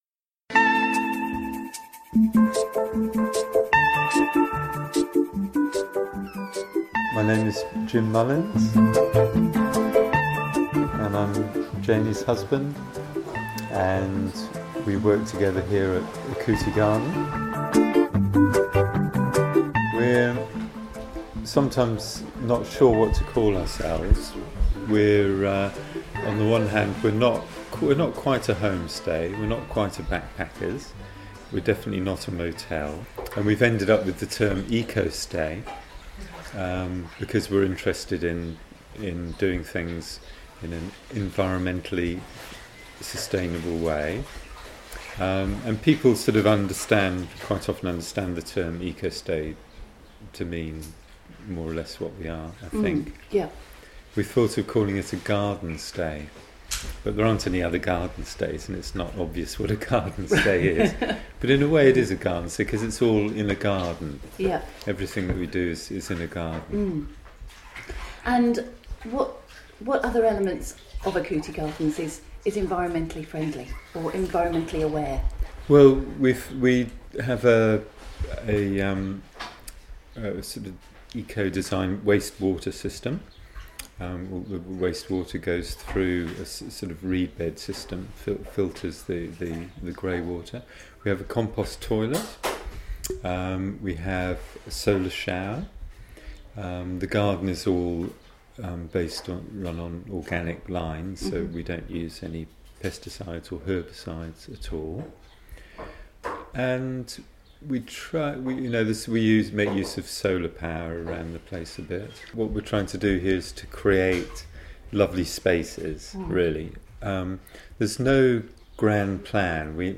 They were good enough to let me drag them away from their work to talk to me a little bit more about Okuti Garden, and this feature was originally broadcast on the January 2014 edition of ReCooper8.